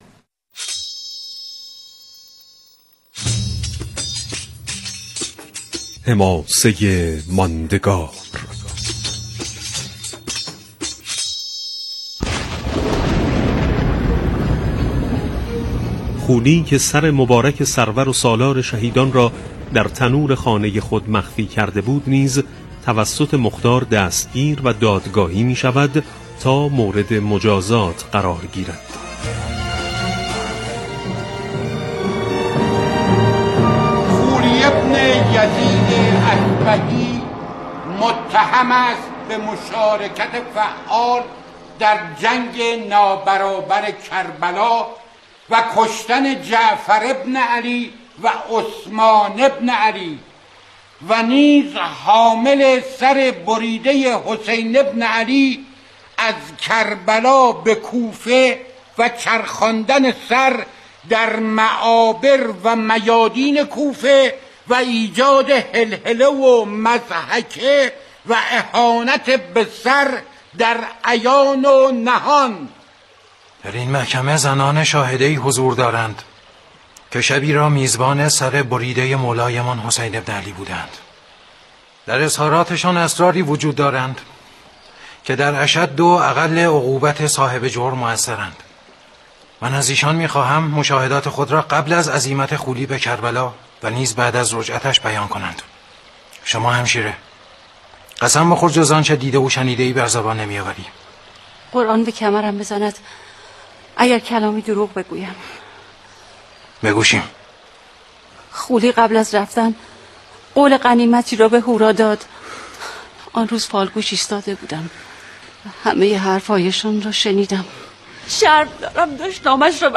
حماسه ماندگار، عنوان برنامه کوتاه شبکه رادیویی قرآن است که طی آن صوت قسمت‌هایی از سریال مختارنامه پخش می‌شود.